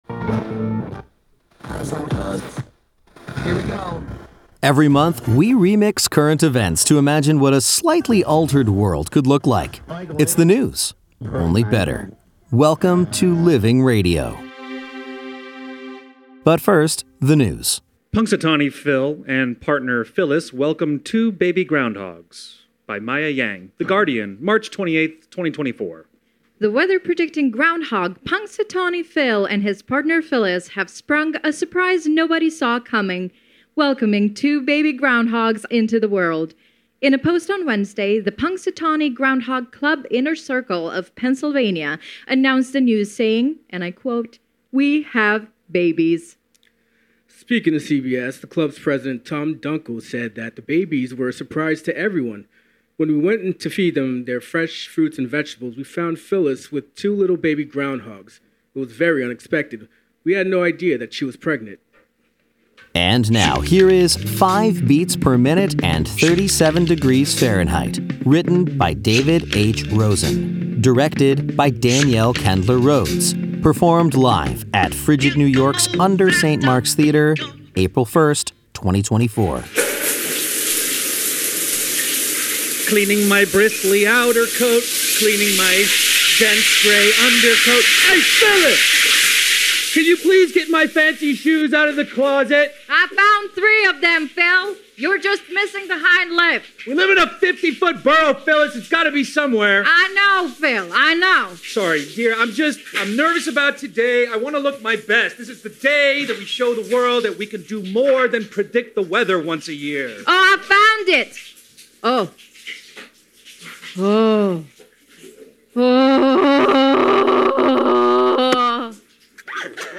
performed live for Living Radio at FRIGID New York’s UNDER St. Mark’s Theater, April 1, 2024